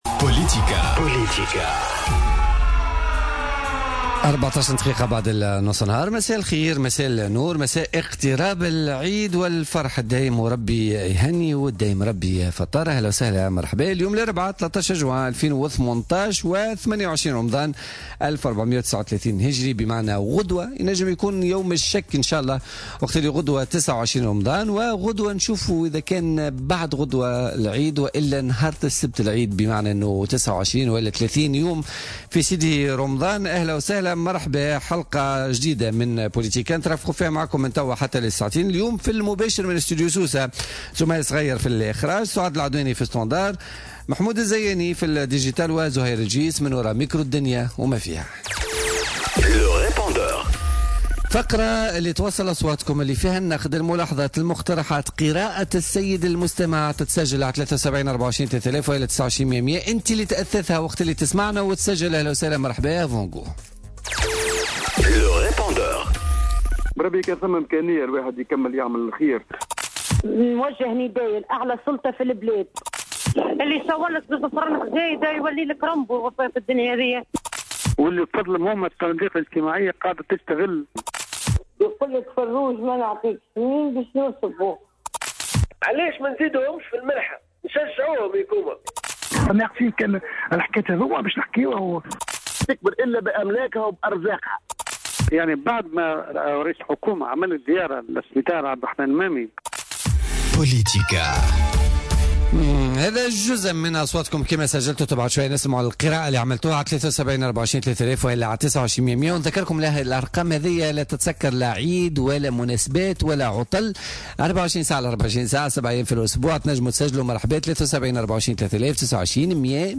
السيد رضا بلحاج منسق حزب تونس أولاً ضيف برنامج بوليتيكا